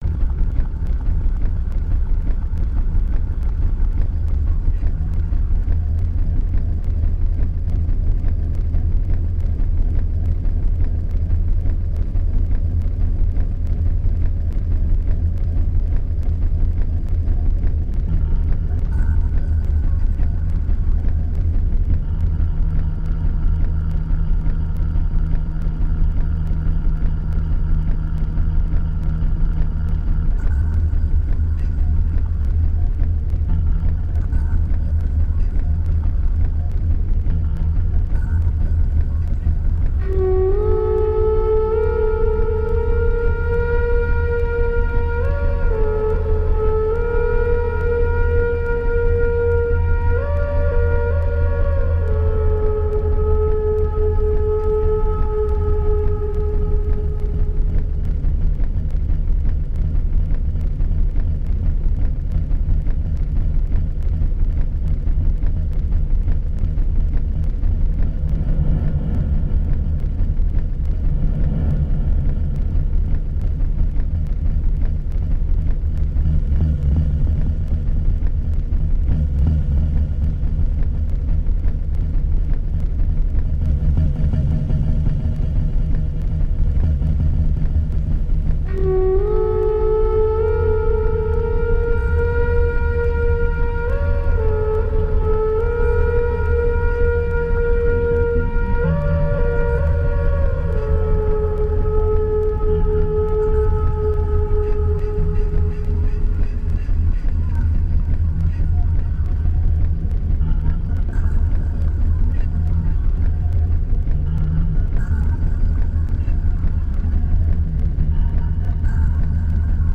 Tagged as: Ambient, New Age, Industrial, Remix, Space Music